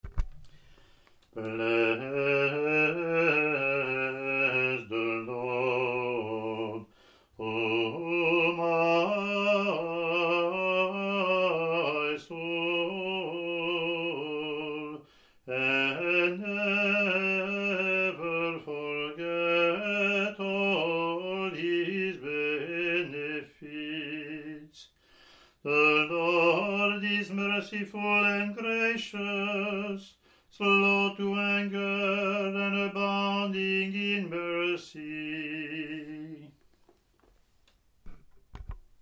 Click to hear Communion (
English antiphon – English verse